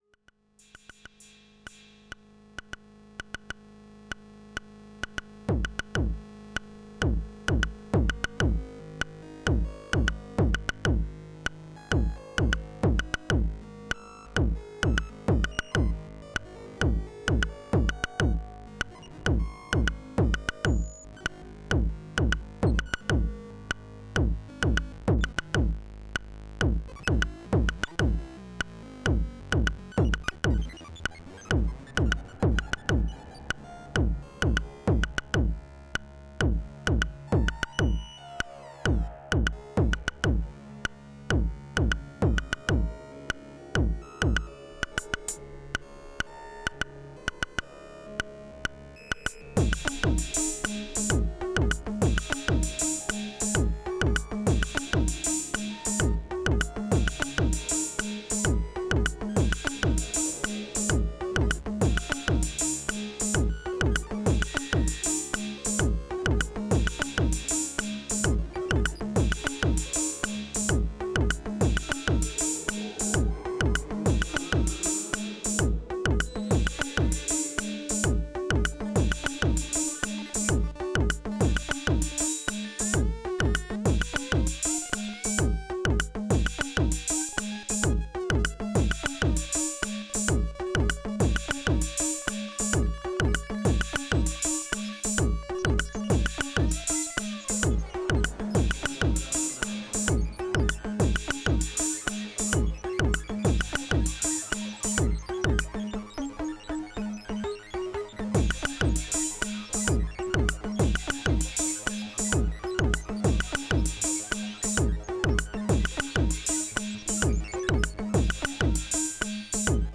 First jam of the year. Syntrx through the AR’s compressor. No MIDI or sync, just playing the dials. It’s super ropey; more to get going with Jamuary, than having anything to say.